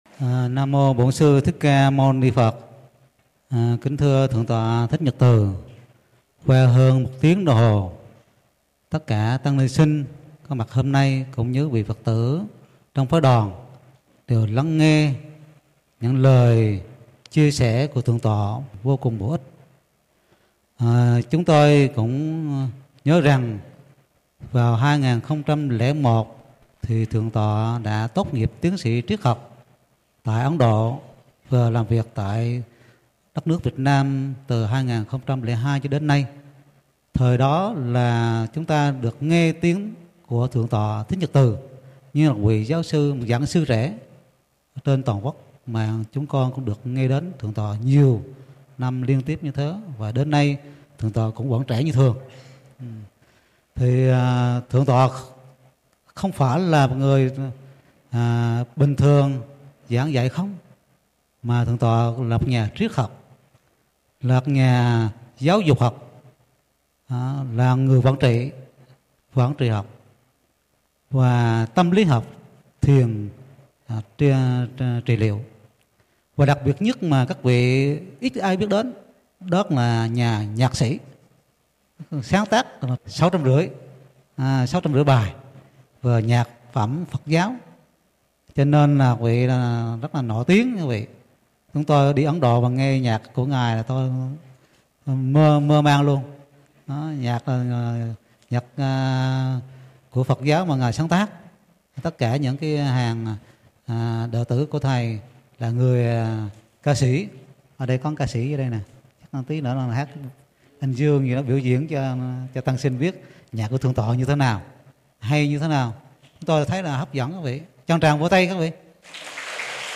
Category: PHÁP THOẠI CÁC GIẢNG SƯ KHÁC